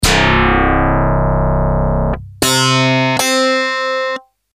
Direct recording, no pre, no eq, both normalized to peak.
DX7 mk2: cleaner on high frequencies.
dx7 mk2